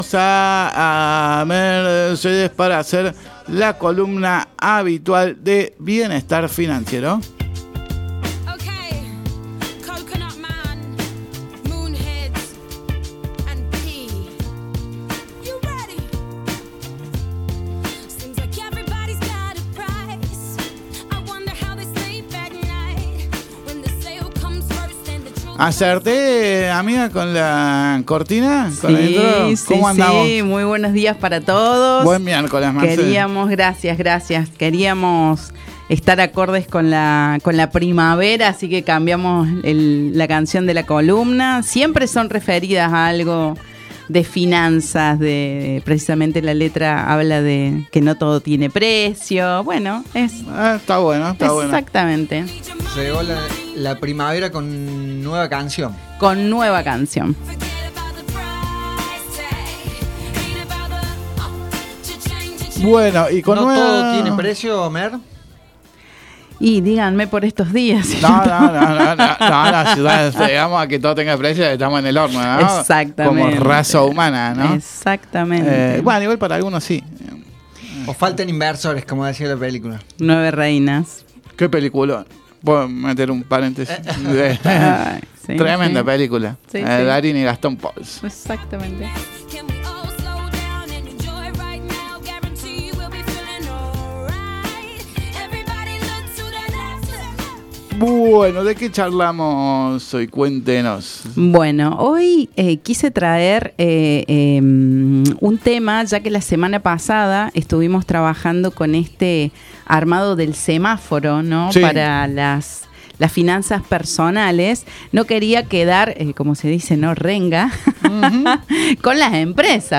en los estudios de Radio Nexo